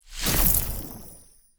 Free Frost Mage - SFX
ice_bolt_07.wav